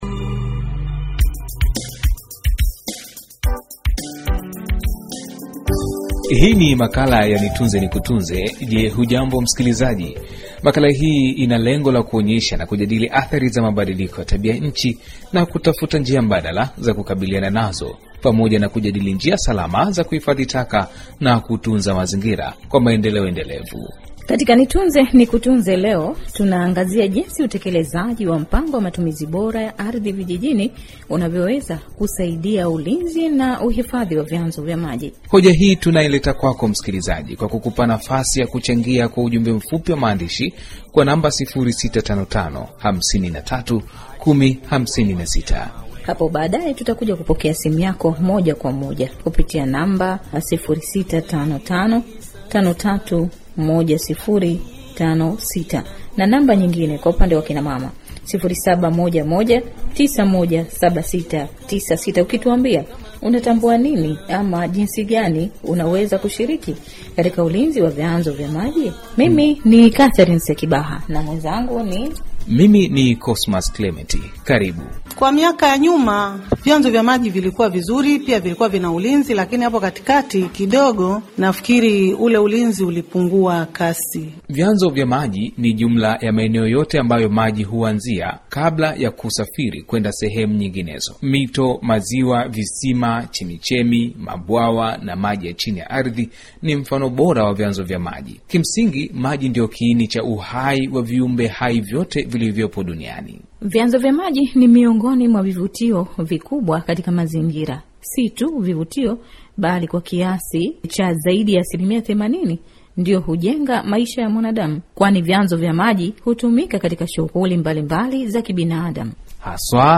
MAKALA: Umuhimu wa mpango wa matumizi bora ya ardhi katika kutunza vyanzo vya maji